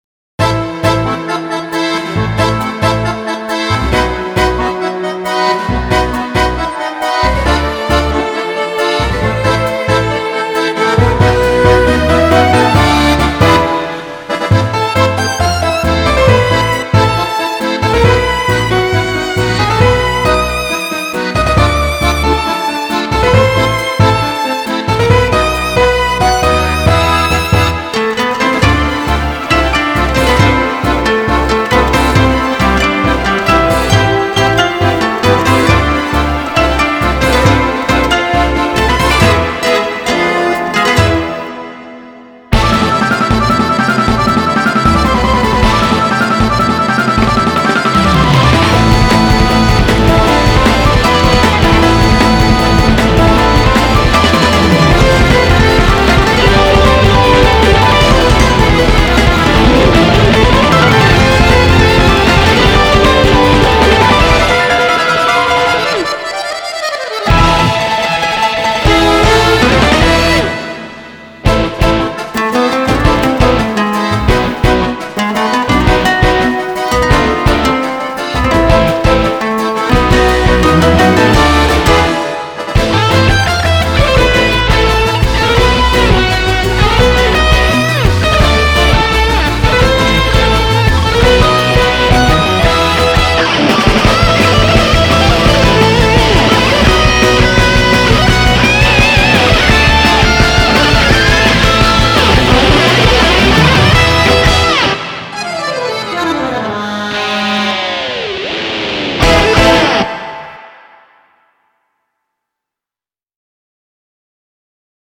BPM136-170
Audio QualityPerfect (High Quality)
PROGRE TANGO